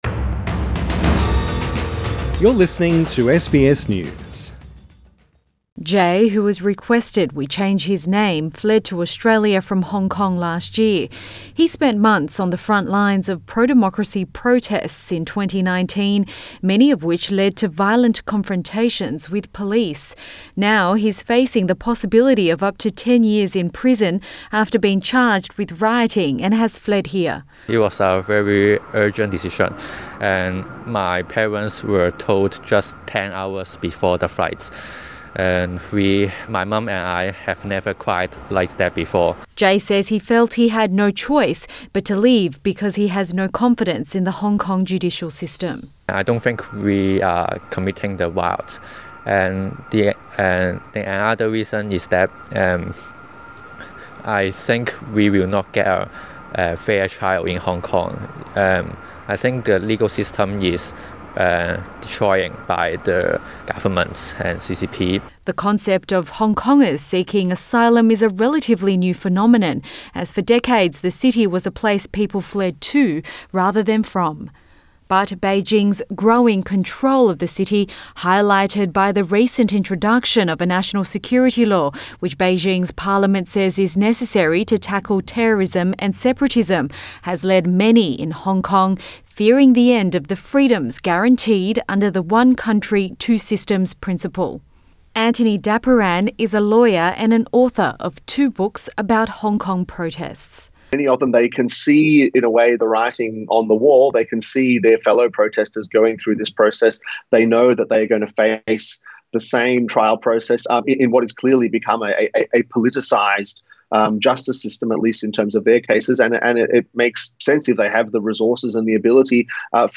Since pro-democracy protests rocked Hong Kong a year ago, almost 9000 people have been arrested and hundreds fled to evade prosecution. SBS News speaks exclusively to a Hong Kong protester who escaped and is now seeking asylum in Australia.